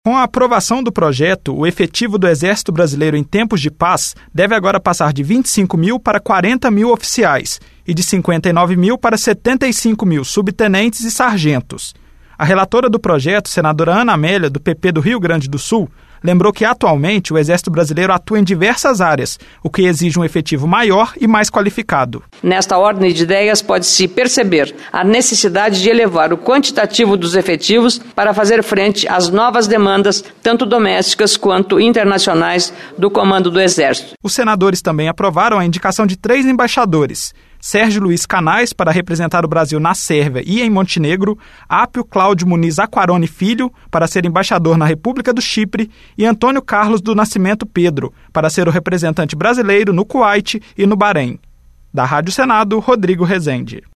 Senadora Ana Amélia